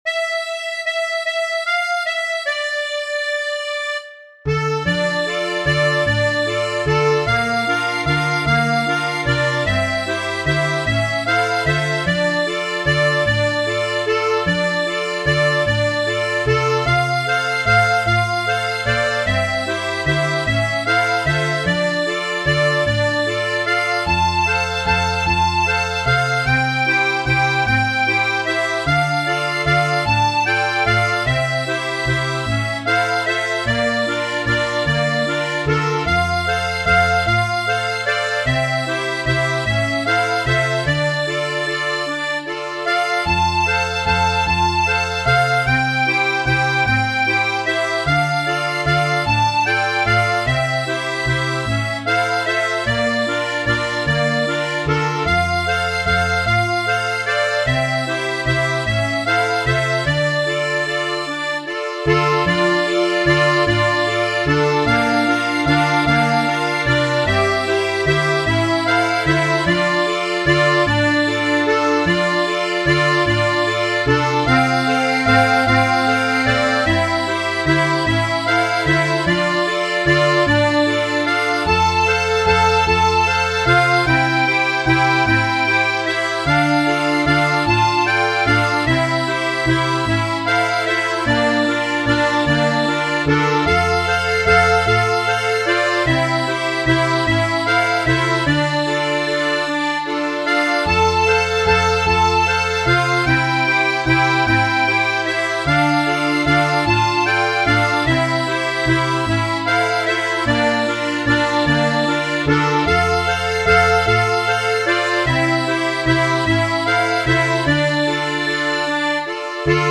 R37 Isoisan valssi R37 (Dm 3st )